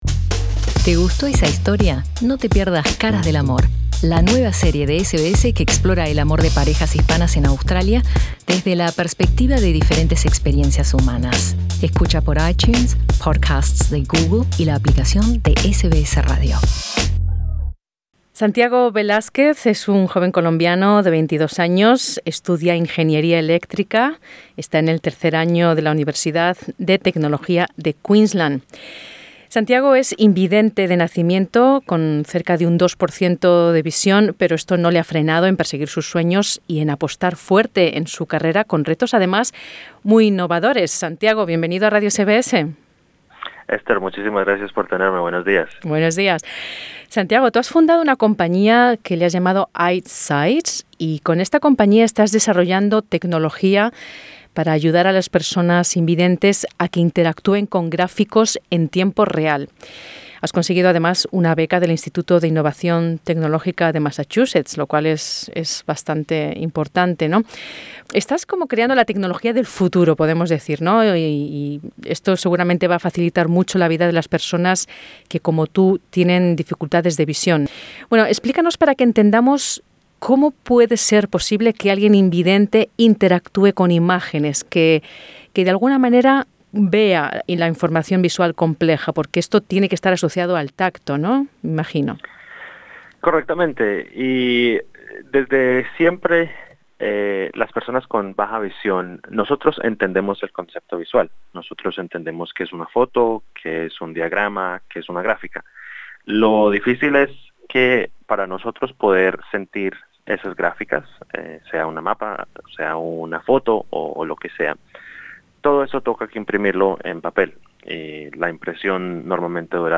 Inmigración le considera una carga para el sistema. Escucha su entrevista en exclusiva para radio SBS Spanish.